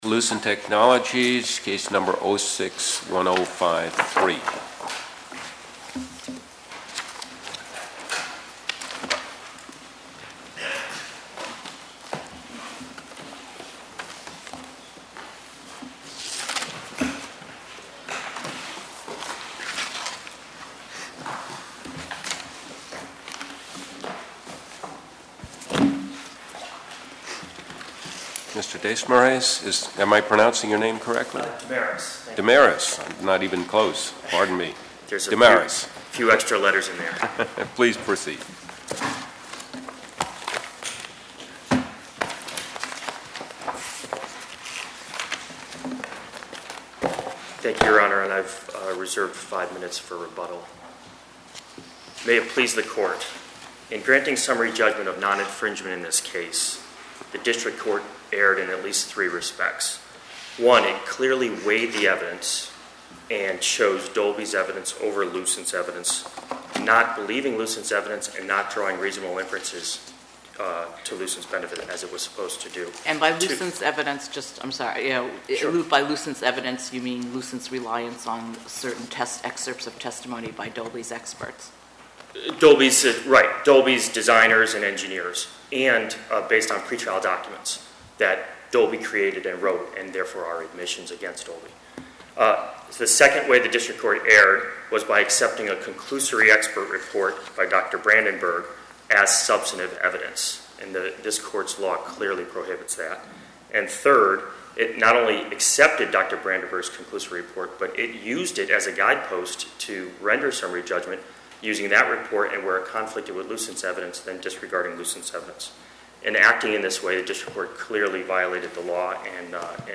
Oral argument audio posted: Dolby Labs v Lucent Technologies (mp3) Appeal Number: 2006-1053 To listen to more oral argument recordings, follow this link: Listen To Oral Arguments.